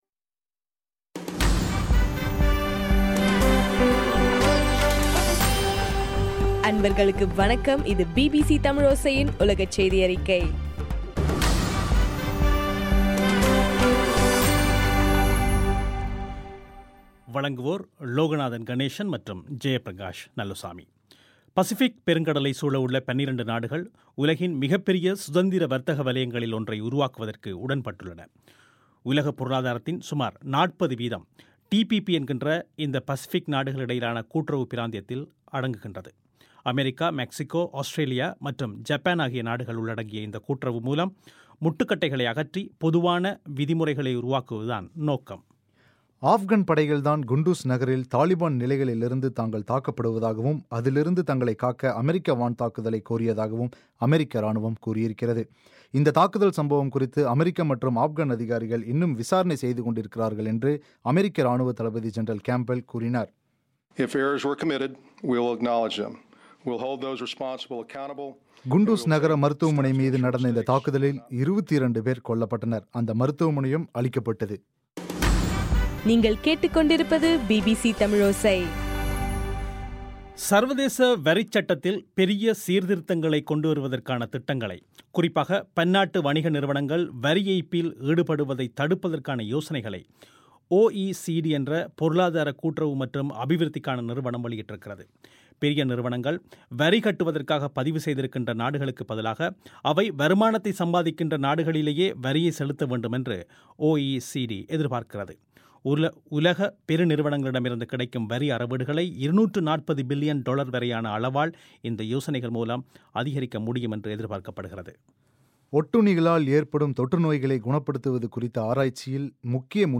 அக்டோபர் 5, 2015 பிபிசி தமிழோசையின் உலகச் செய்திகள்